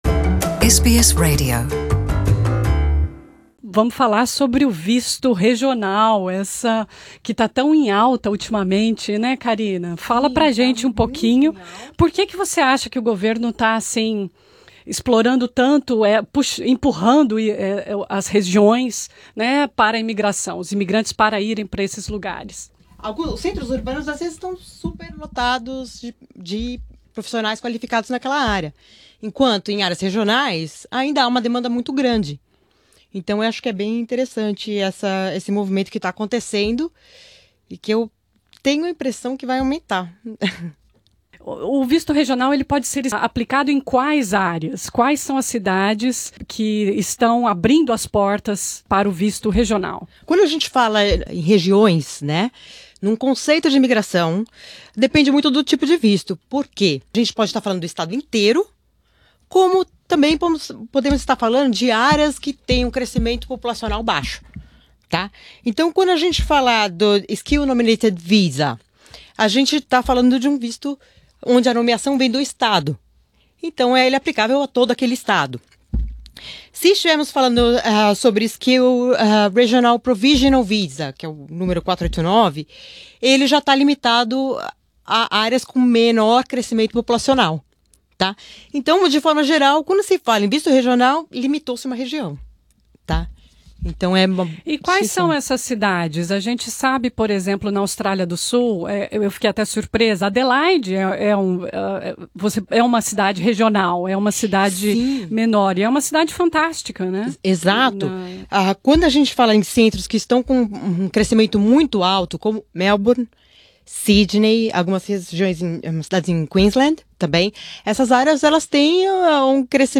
Leia abaixo alguns dos tópicos discutidos durante a entrevista gravada. Quais são as cidades e/ou estados australianos que aceitam esse tipo de visto?